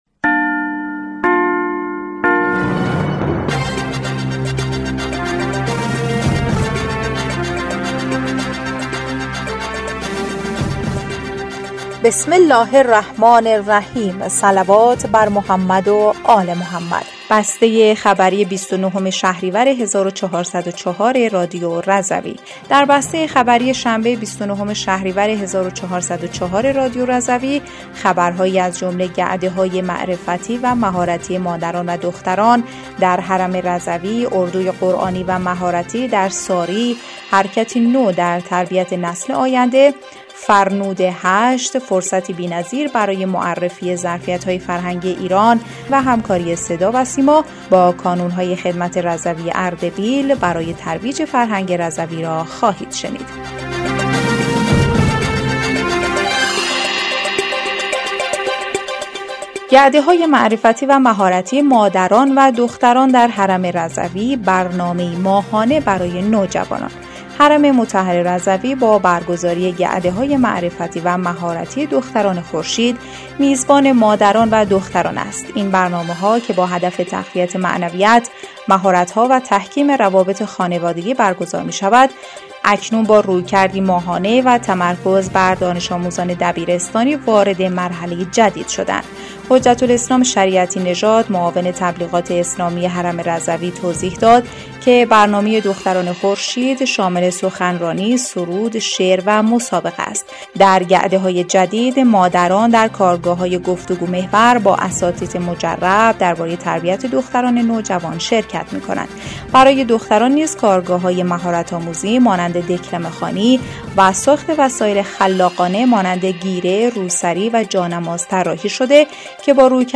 بسته خبری ۲۹ شهریور ۱۴۰۴ رادیو رضوی/